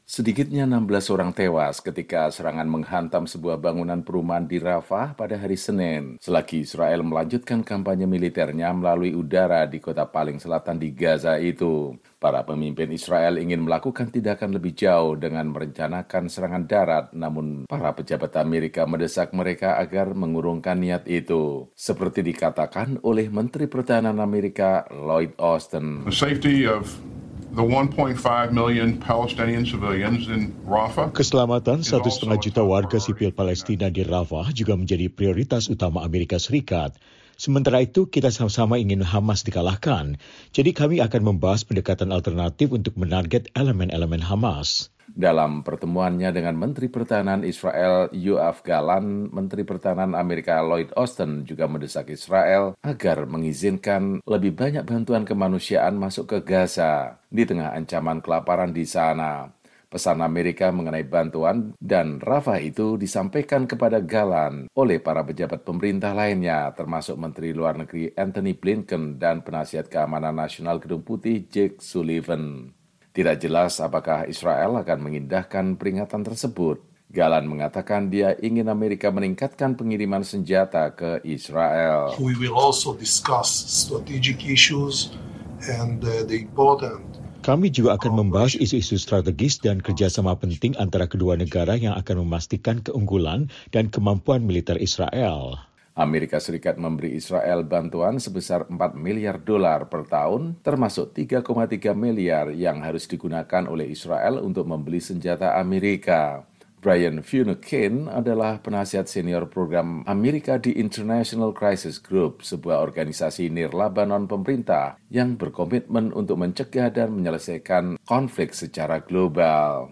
“Keselamatan 1,5 juta warga sipil Palestina di Rafah juga menjadi prioritas utama Amerika Serikat. Sementara itu, kita sama-sama ingin Hamas dikalahkan. Jadi kami akan membahas pendekatan alternatif untuk menarget elemen-elemen Hamas," kata Menteri Pertahanan AS Lloyd Austin.